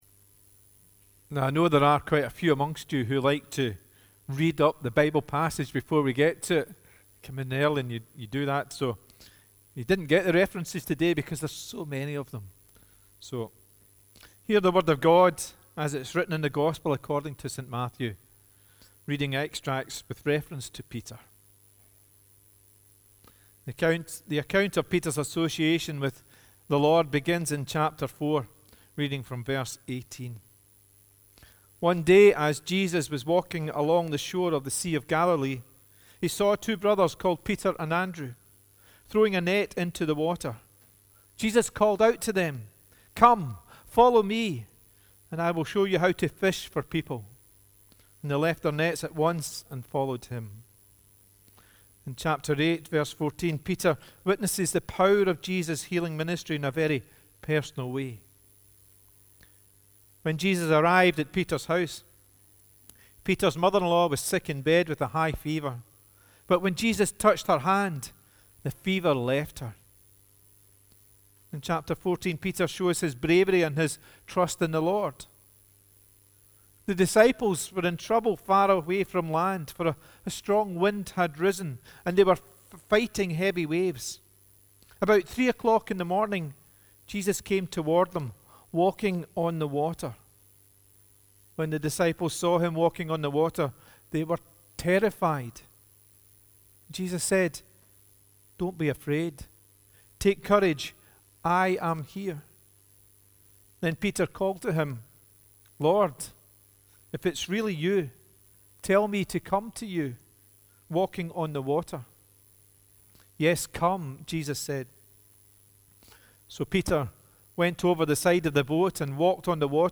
The Scripture Readings prior to the Sermon are references to Peter taken from the Gospel of Matthew